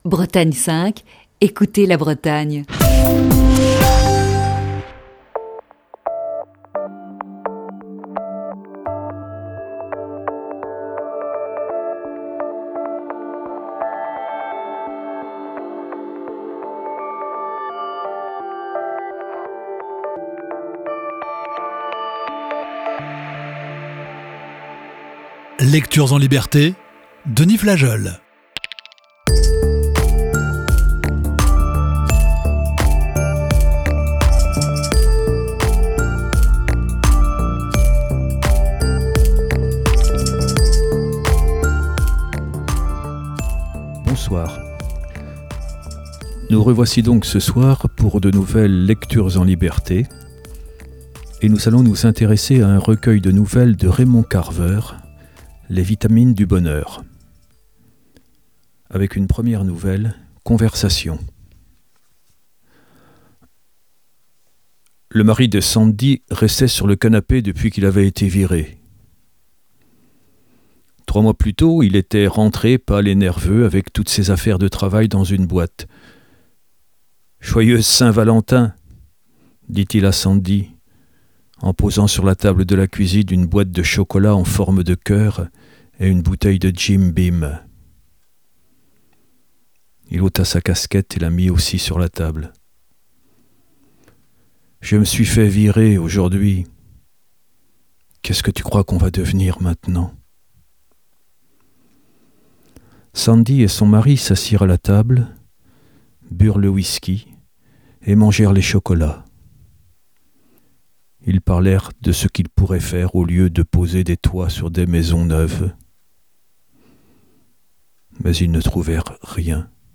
lit deux récits extraits du recueil de nouvelles